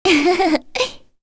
Kirze-Vox_Happy1_kr.wav